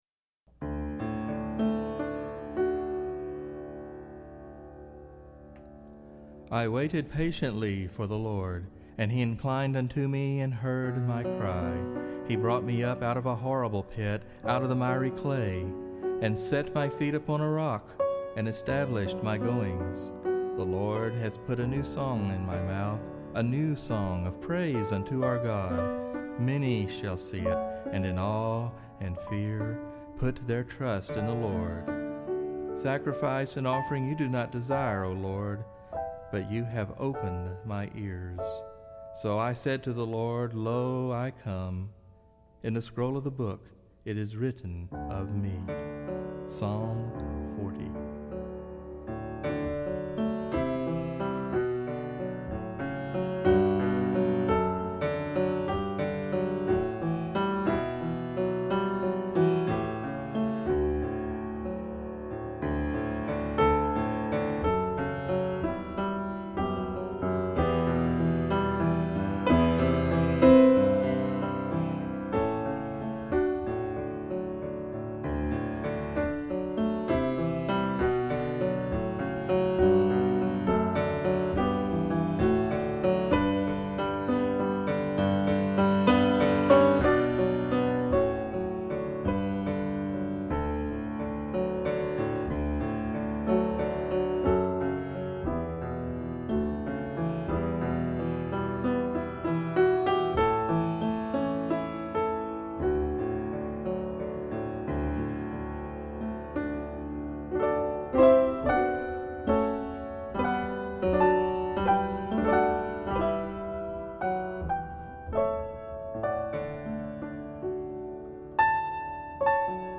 as well as performing on the 1899 Steinway grand piano.